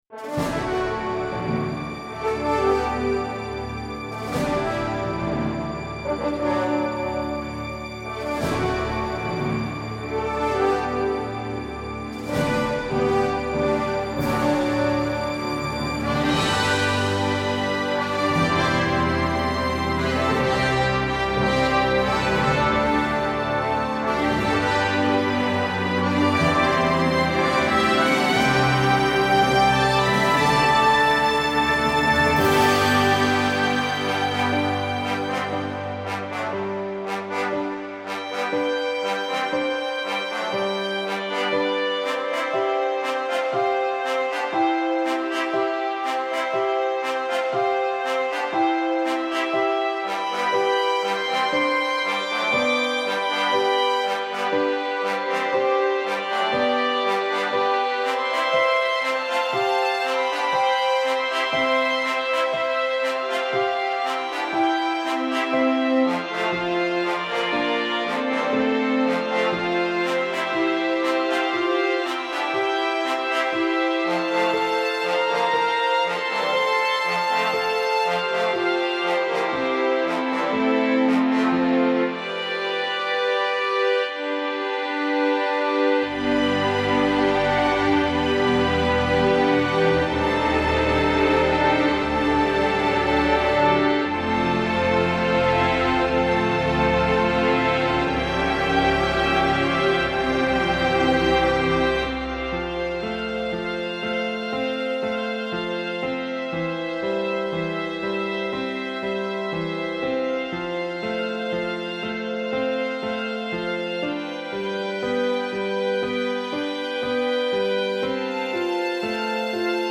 Cinematic transitions (instrumental) - Orchestrated: 14th-23rd July 2012.